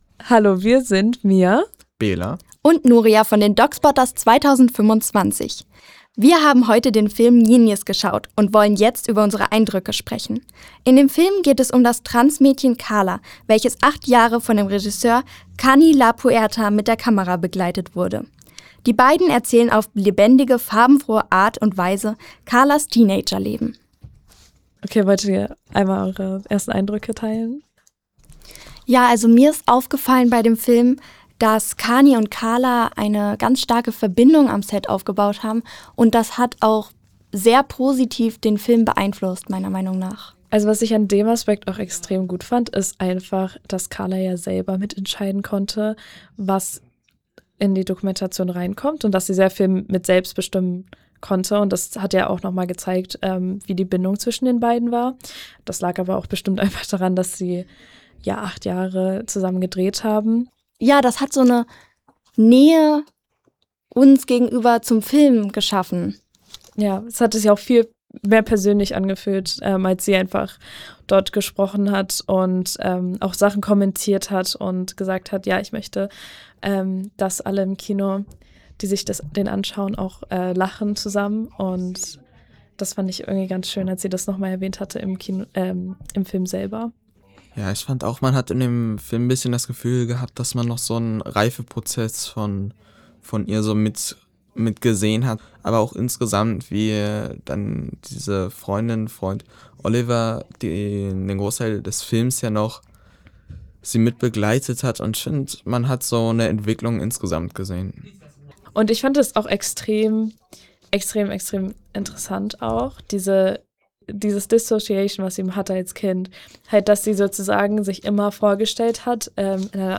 Der Film hat uns auch im Nachhinein noch so beschäftigt, dass wir ein Kolleg*innengespräch geführt haben, in dem wir unsere Eindrücke und Gefühle besprochen haben.